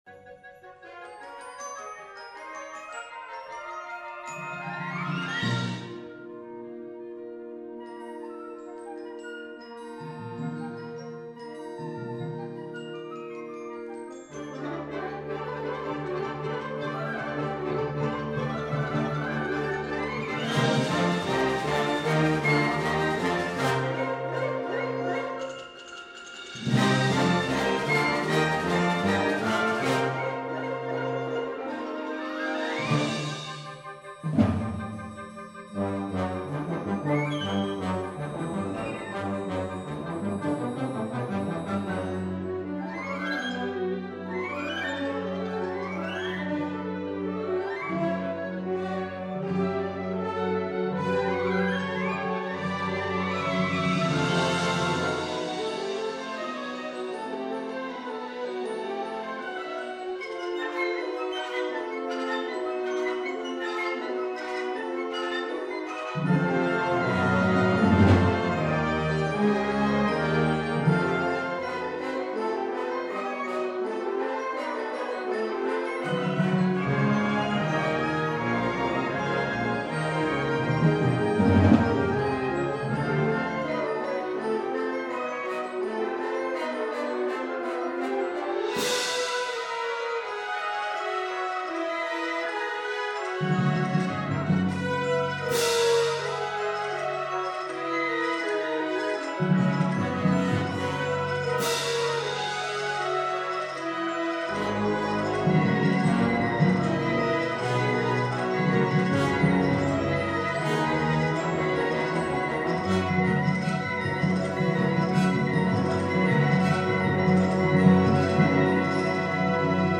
Symphonic Band
Fantasy for Symphonic Band